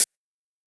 UHH_ElectroHatB_Hit-26.wav